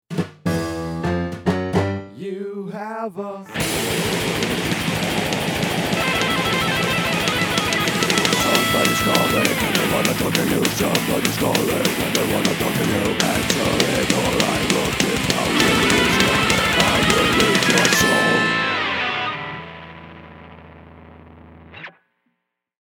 Here are two mixes: One as normal and one with a high pass filter at 150 Hz tailored for cell phones.